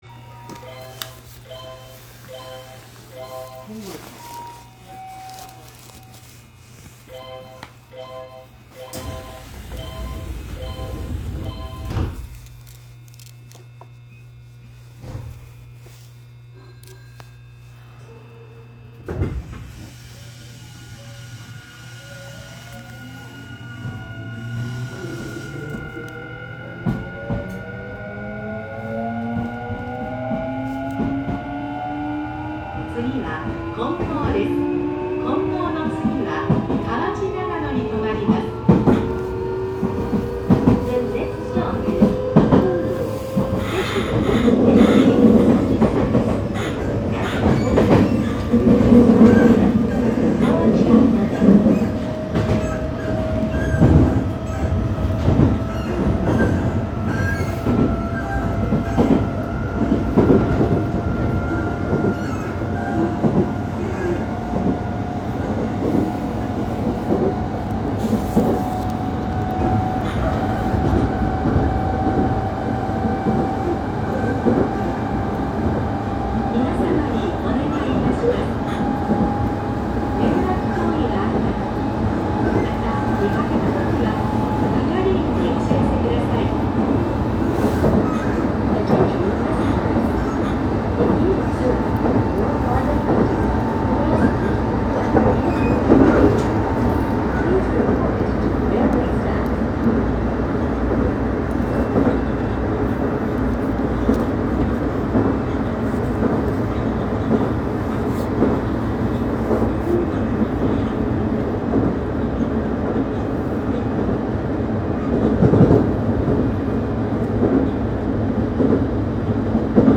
【車両の音】北野田→金剛の走行音を追加。
・8000系走行音
【高野線】北野田→金剛
よくある前期の日立IGBTとなり、特徴はありませんが音量は大きいので聞きごたえはあるかと思います。